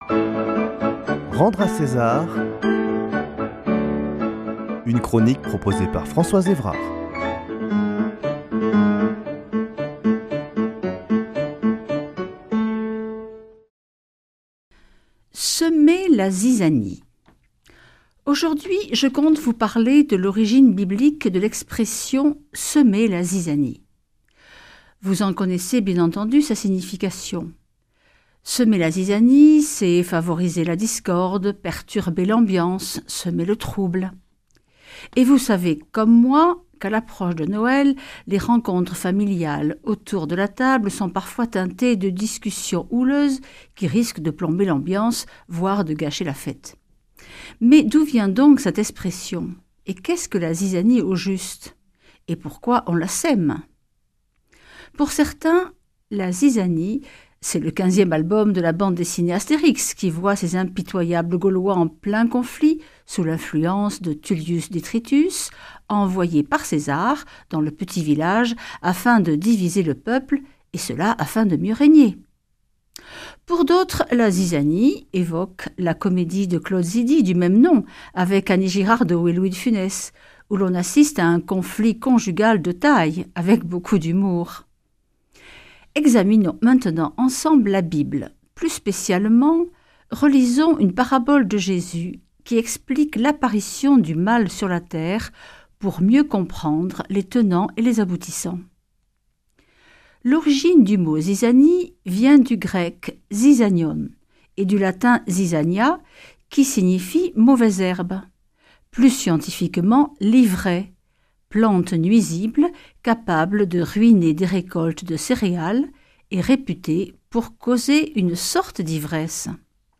Chroniqueuse